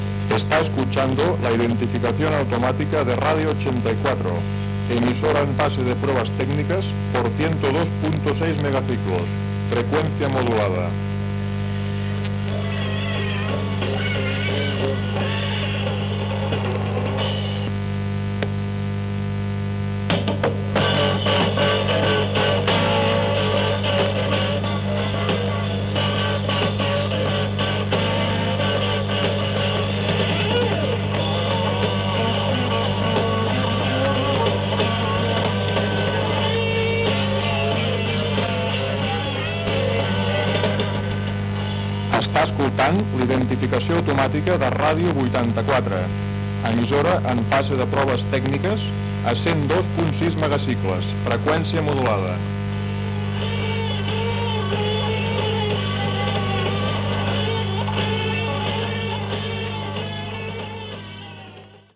4550ea07fcf23a49fe0d3d4e3054e05eeeb7e6b5.mp3 Títol Radio 84 Emissora Radio 84 Titularitat Tercer sector Tercer sector Musical Descripció Identificació automàtica en proves.